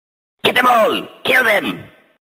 Yes Low Pitch 1x Minion